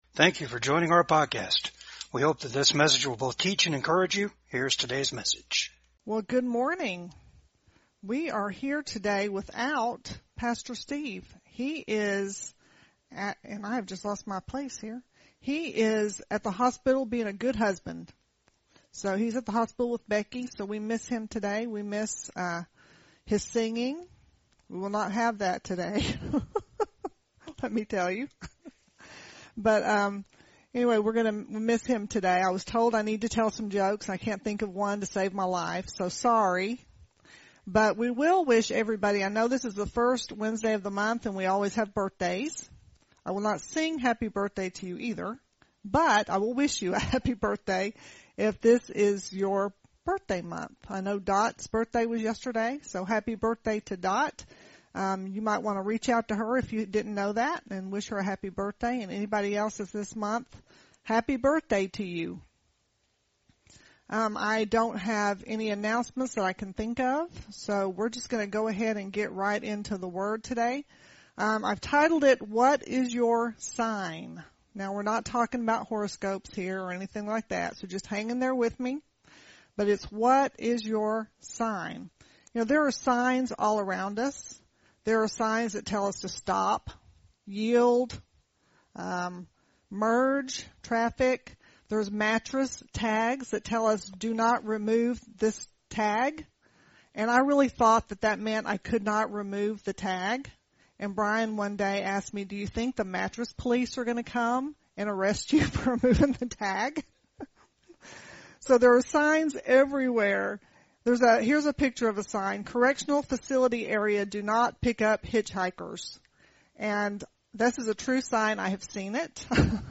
Matthew 15:18 Service Type: VCAG WEDNESDAY SERVICE WE CANNOT AFFORD TO MERELY PRAY FOR OURSELVES.